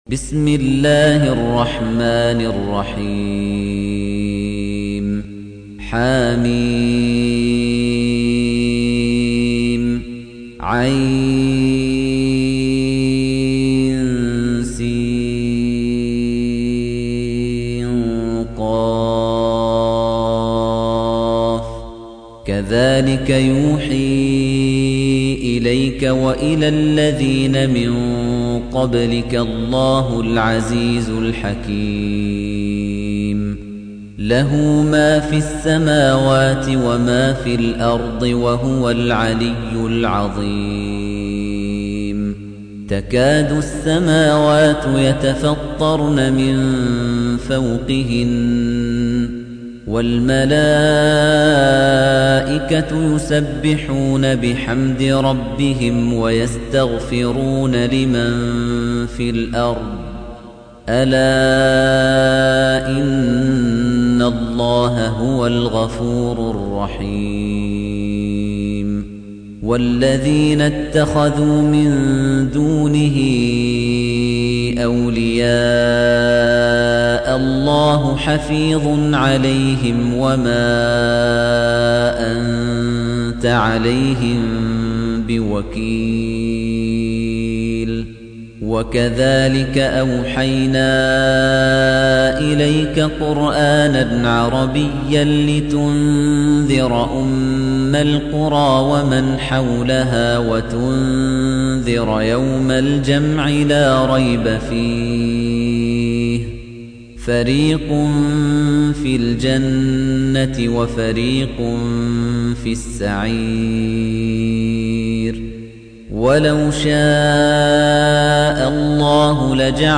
Surah Repeating تكرار السورة Download Surah حمّل السورة Reciting Murattalah Audio for 42. Surah Ash-Sh�ra سورة الشورى N.B *Surah Includes Al-Basmalah Reciters Sequents تتابع التلاوات Reciters Repeats تكرار التلاوات